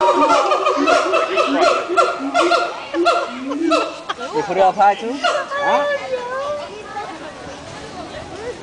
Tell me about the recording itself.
Monkeys Miami ZooMOV06764.MPG